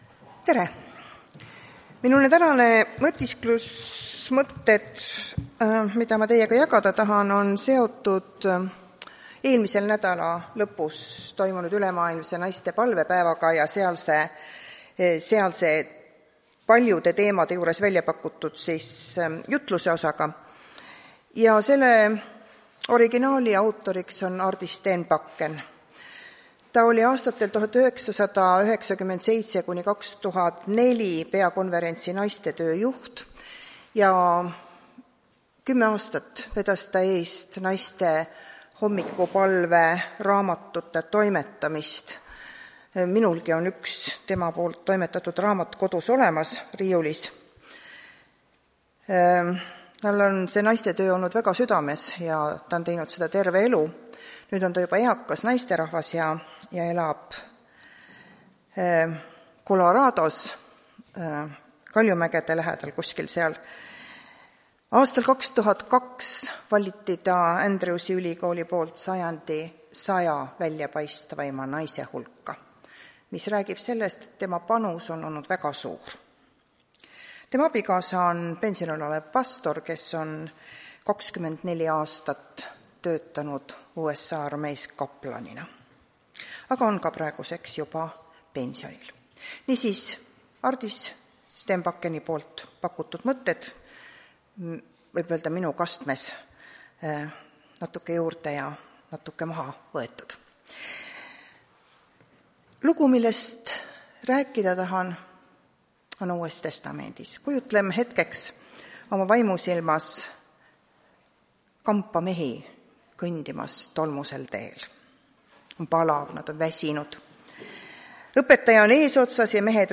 Tartu adventkoguduse 14.03.2026 teenistuse jutluse helisalvestis.